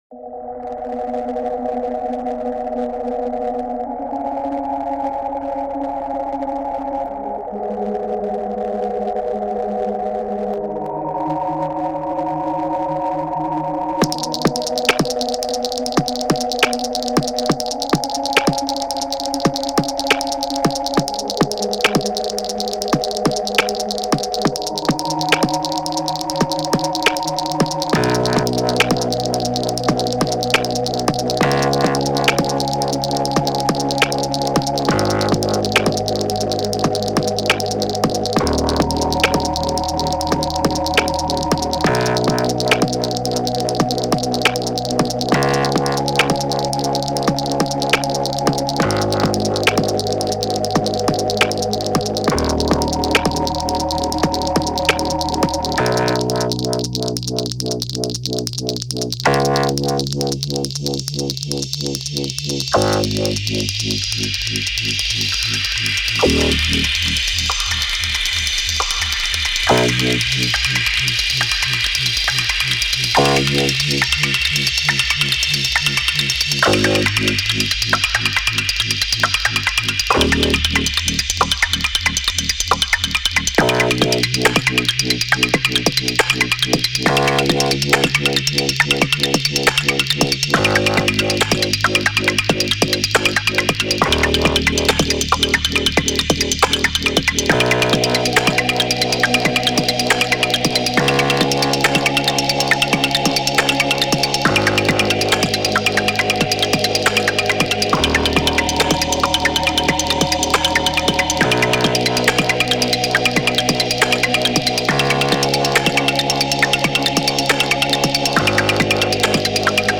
Here's a mix of all of the submissions, ordered by upload date.